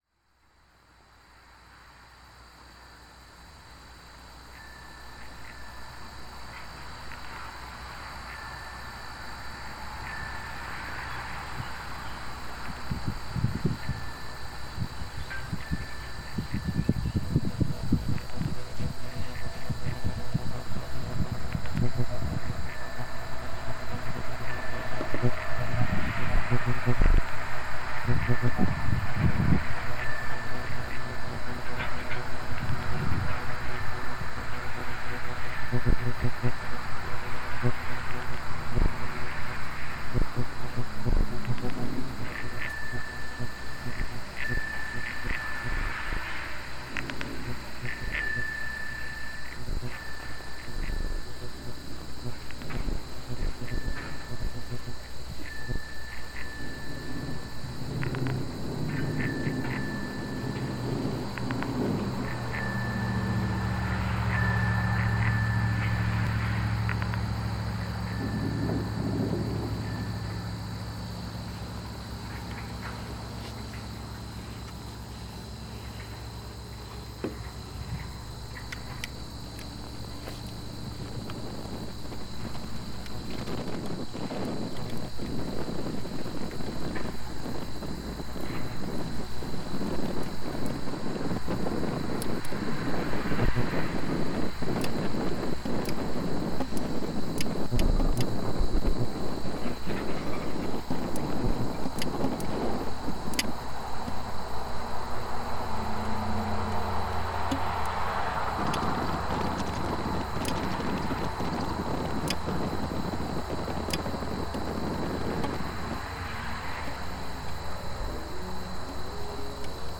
A core group of sound and transmission artists
come together with other remote participants to create a monthly live radio series